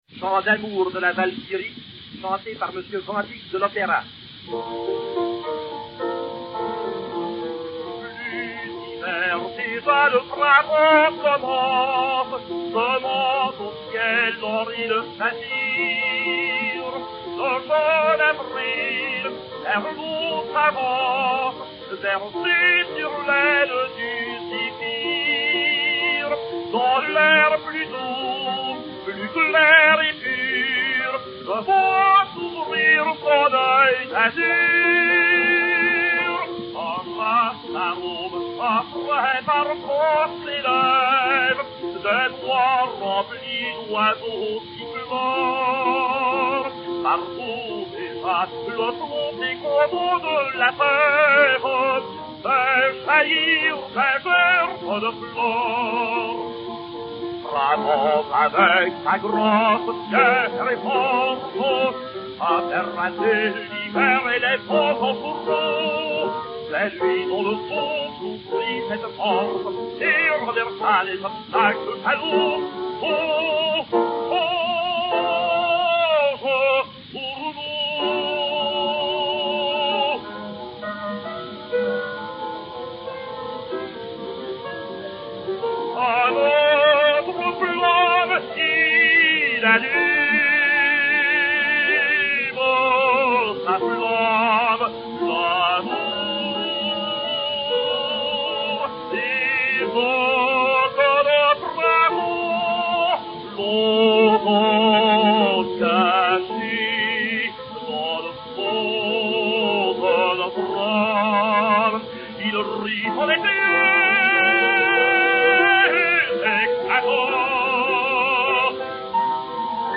Ernest Van Dyck (Siegmund) et Piano
Pathé 795 mat. 60602, enr. à Londres en 1903